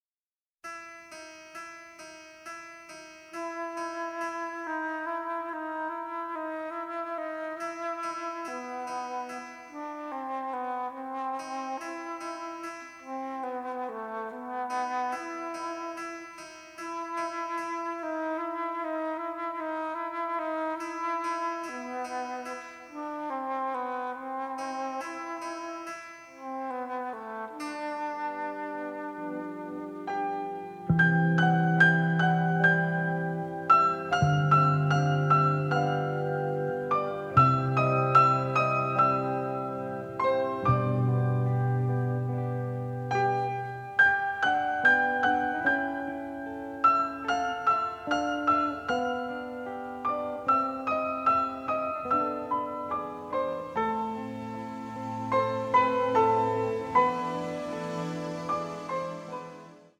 wistful and playful love theme